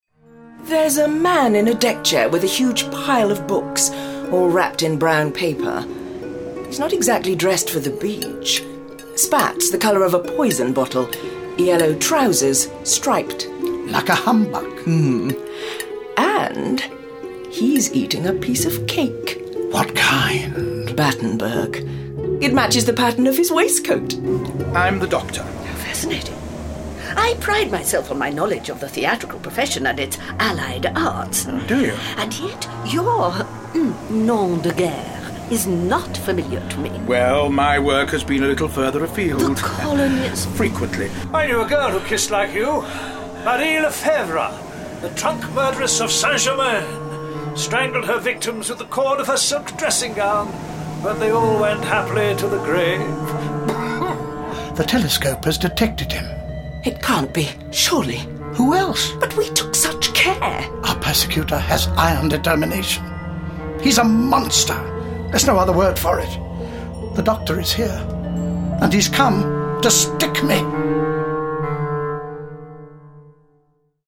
Award-winning, full-cast original audio dramas from the worlds of Doctor Who
Starring Colin Baker Nicola Bryant